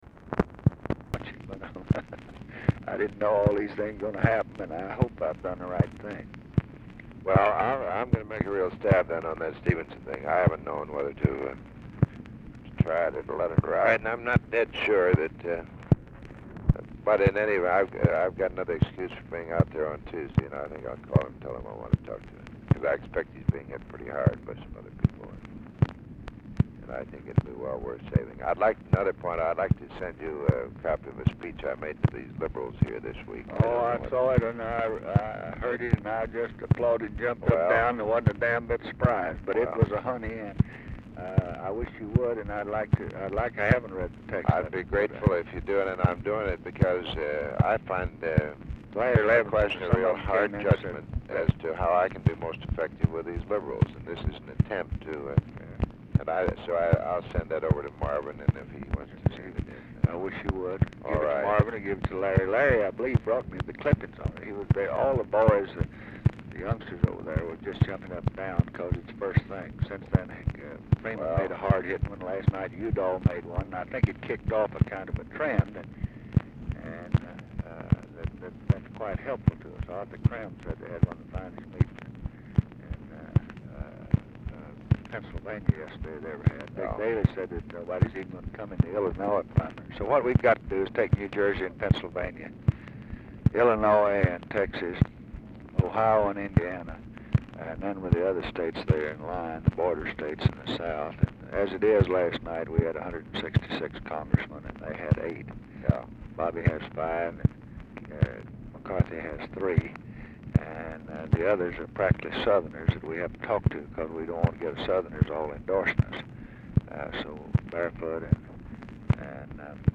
Telephone conversation # 12837, sound recording, LBJ and WILLARD WIRTZ, 3/23/1968, 10:41AM | Discover LBJ
RECORDING ENDS BEFORE CONVERSATION IS OVER
Format Dictation belt
Location Of Speaker 1 Mansion, White House, Washington, DC